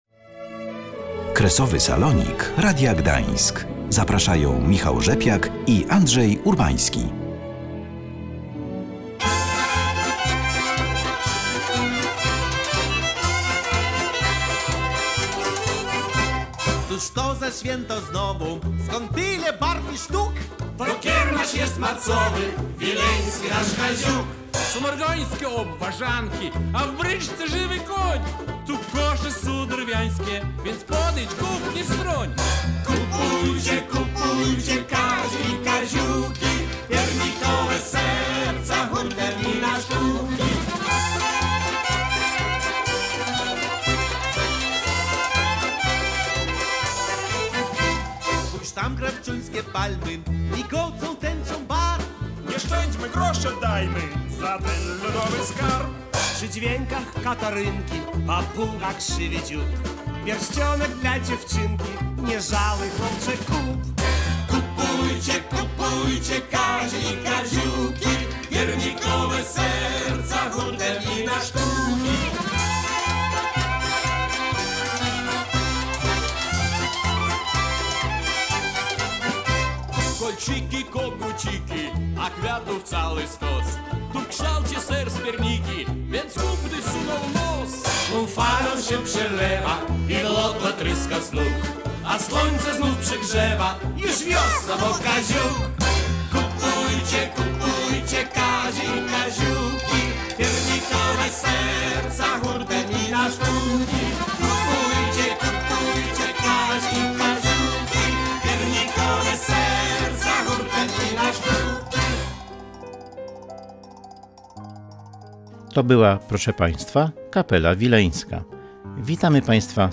W audycji opowiedzieliśmy, co można kupić na Kaziuku. A o wiciu wileńskich palm wielkanocnych i kultywowaniu tradycji opowiedziały palmiarki z Izby Palm i Użytku Powszechnego w Ciechanowiszkach.